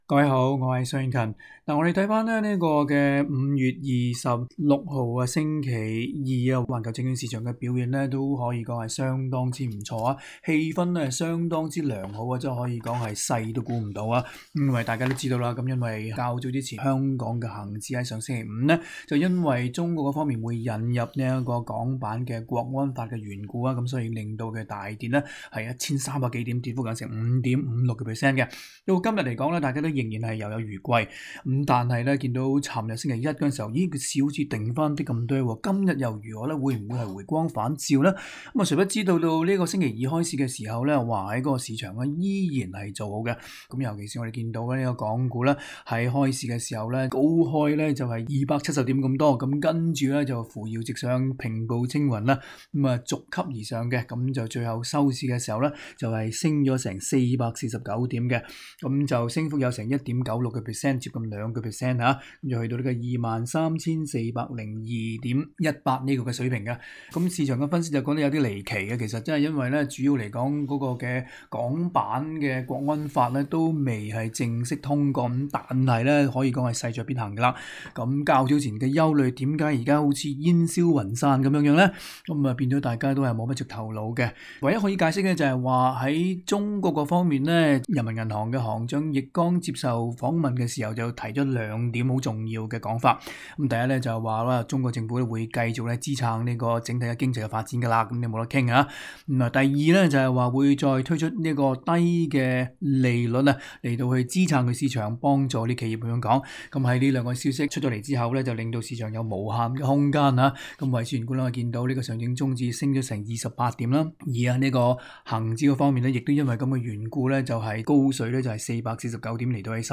詳情請收聽今天的訪問。